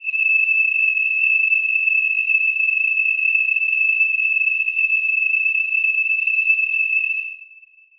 Choir Piano
E7.wav